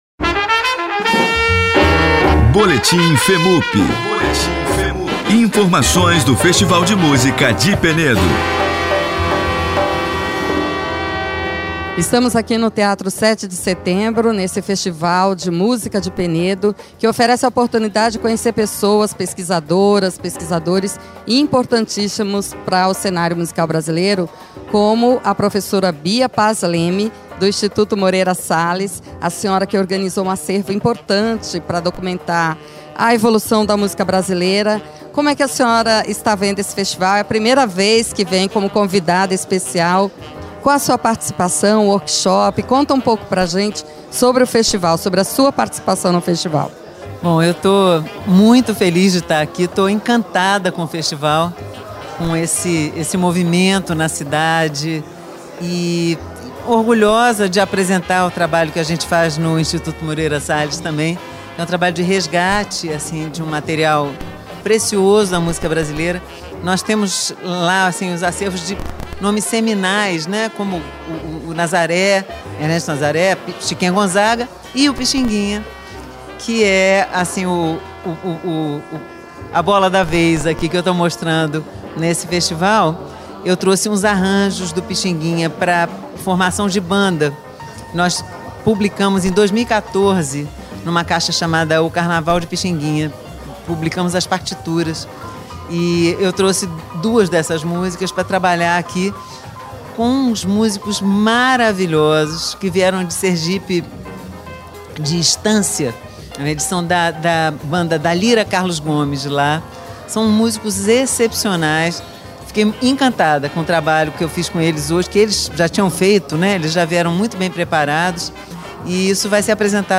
no Teatro Sete de Setembro